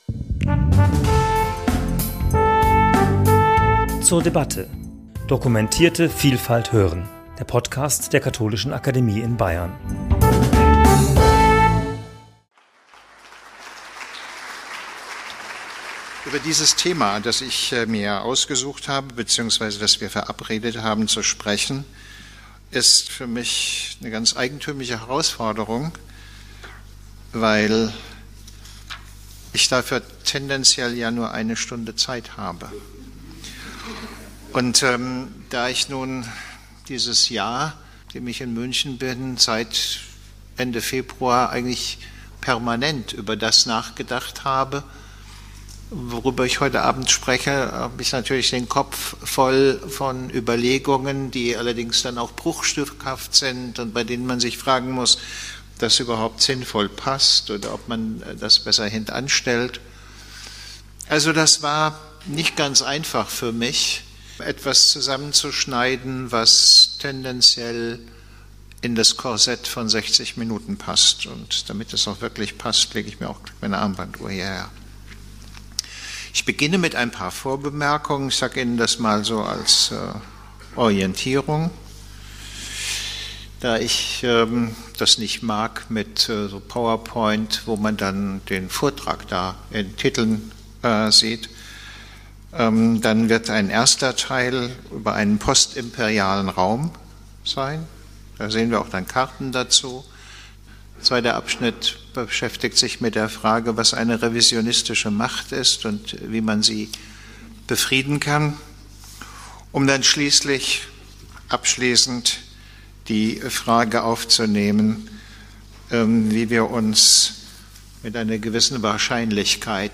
Der Berliner Politikwissenschaftler Prof. Dr. Herfried Münkler wird uns dabei aber nicht nur die vielschichtigen Hintergründe zur aktuellen Lage erläutern, sondern auch die großen Herausforderungen benennen, vor welchen Europa und damit wir alle gemeinsam stehen.